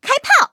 M2中坦开火语音2.OGG